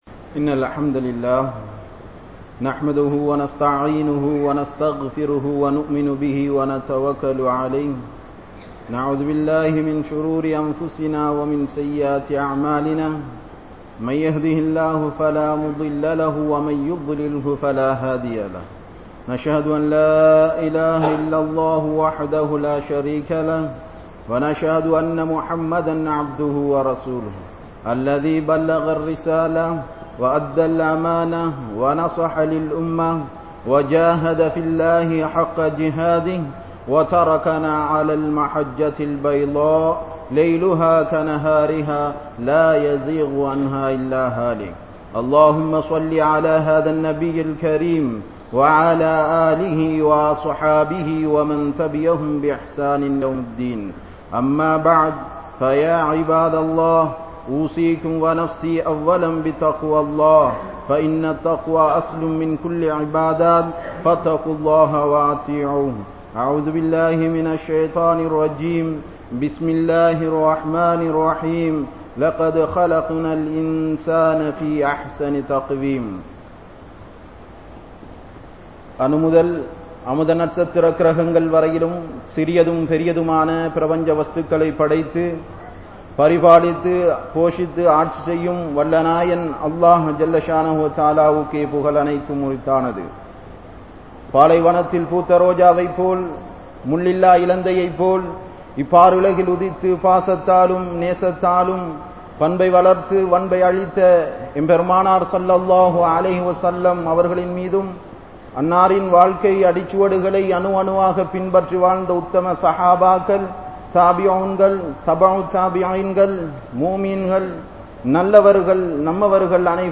Thiyaaham (தியாகம்) | Audio Bayans | All Ceylon Muslim Youth Community | Addalaichenai